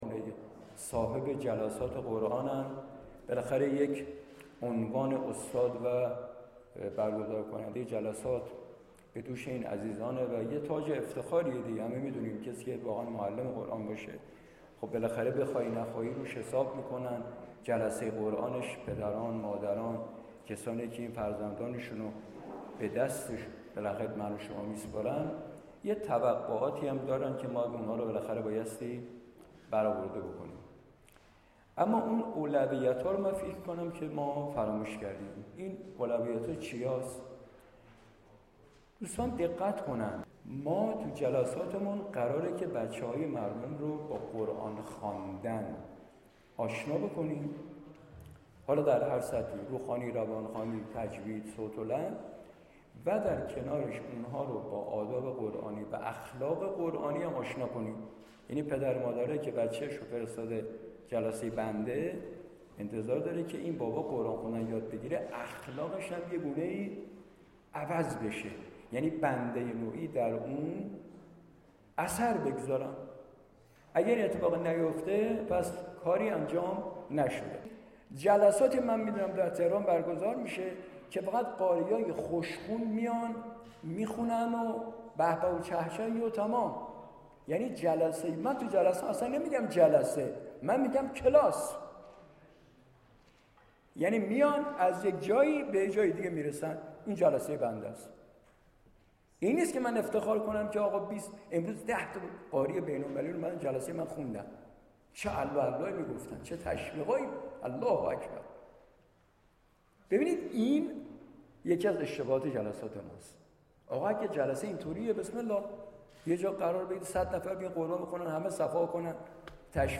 به گزارش خبرنگار فرهنگی باشگاه خبرنگاران تسنیم «پویا» دومین جلسه هم‌اندیشی و تبادل نظر اساتید جلسات قرآن تهران پنجشنبه شب، پس از نماز مغرب و عشاء در مجموعه شهدای هفتم تیر سرچشمه برگزار شد.